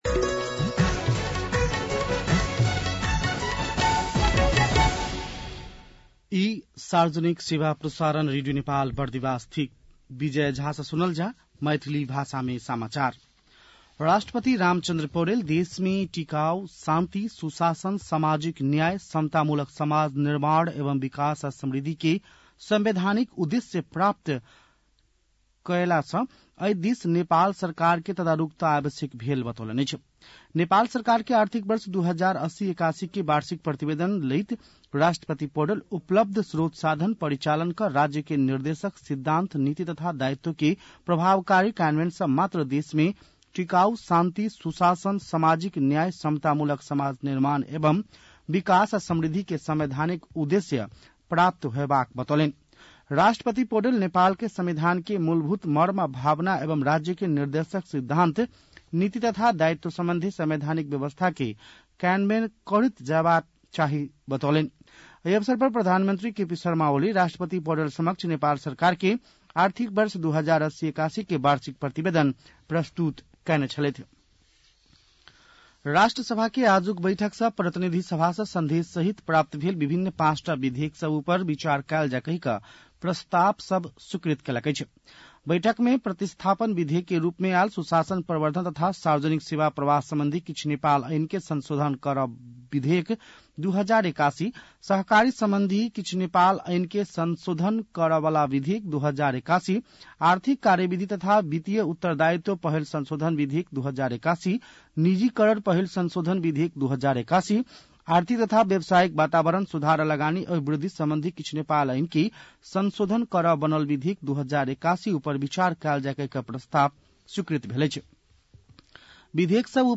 मैथिली भाषामा समाचार : १० चैत , २०८१